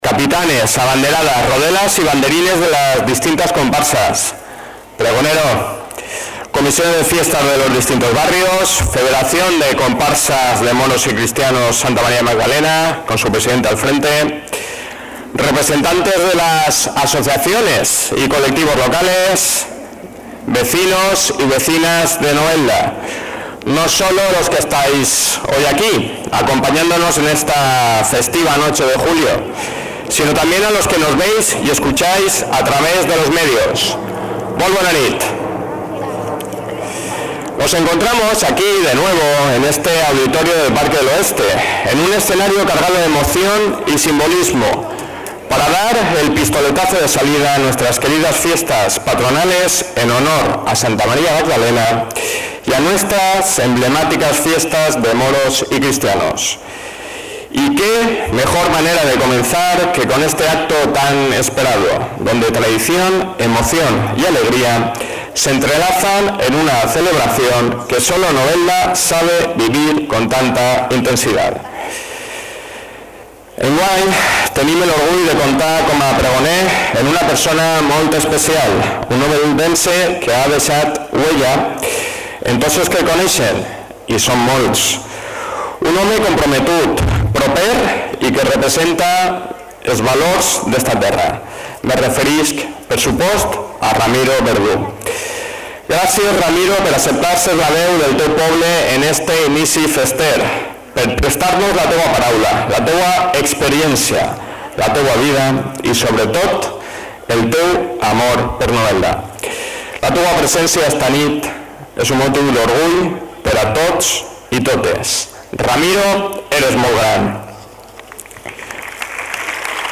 El Parque del Oeste acogió un año más la gala de elección de reinas de las fiestas, proclamación de cargos festeros y pregón con la que se daba inicio a las Fiestas Patronales y de Moros y Cristianos en honor a Santa María Magdalena, un acto lleno de emoción, tradición y orgullo noveldero.
Corte-Pregon.mp3